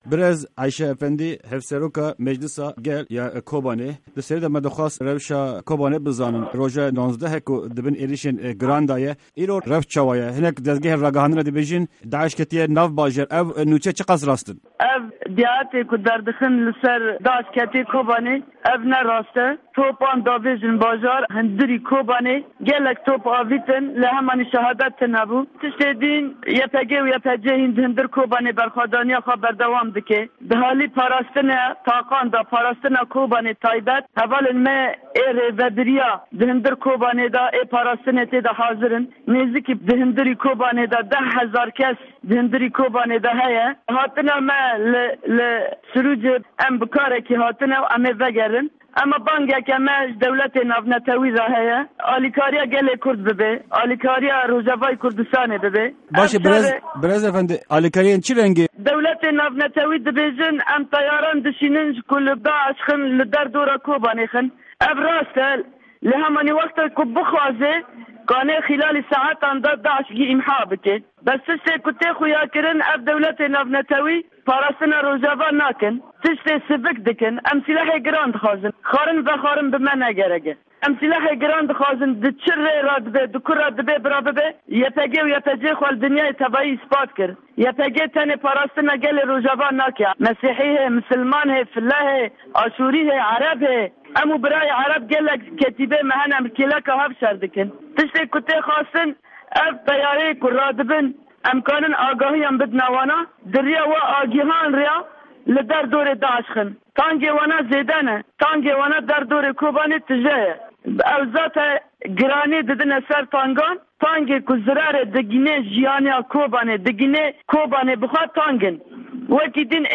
Di hevpeyvîna Dengê Amerîka de Hevseroka Meclîsa Gel ya Kobanê Ayşe Efendî rewşa Kobanê dinirxîne û agahîyên herî dawî dide.